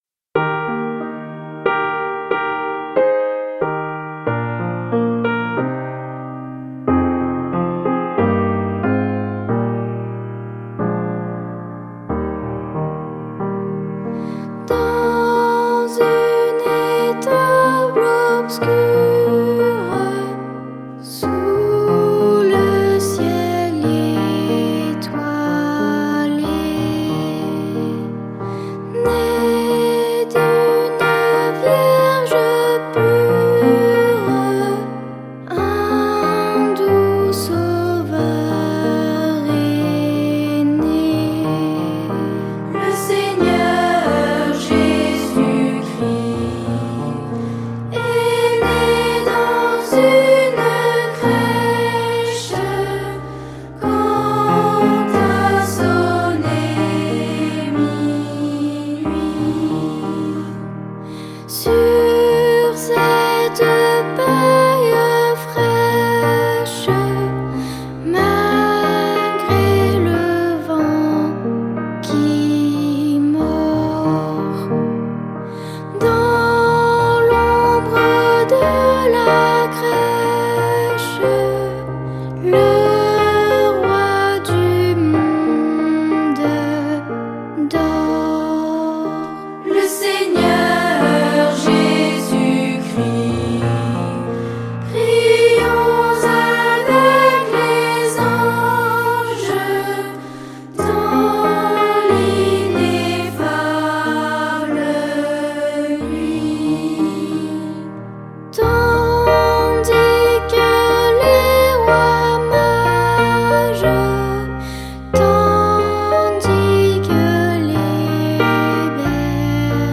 Ce livre, délicatement illustré, présente avec tendresse des chants traditionnels, disponibles sur CD-Rom et sur notre site Internet.